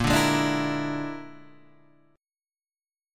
A#7b5 chord {6 5 6 7 5 6} chord